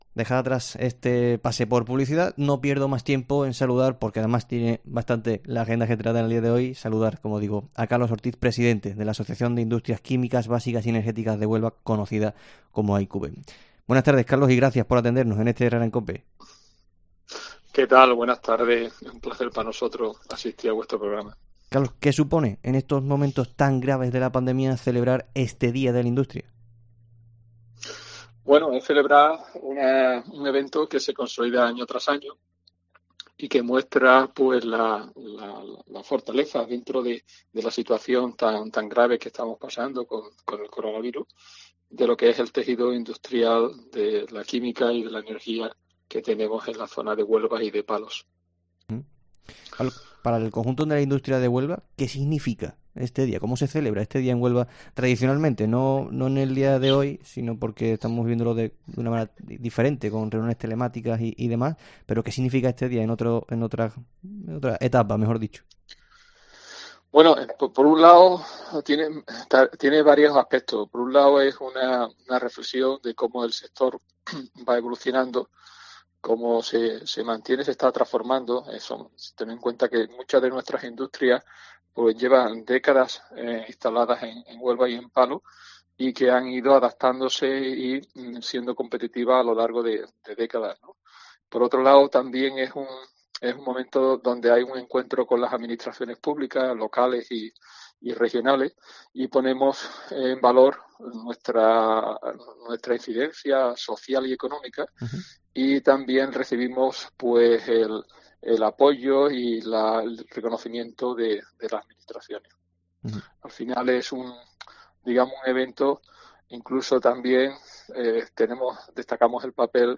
DIA DE LA INDUSTRIA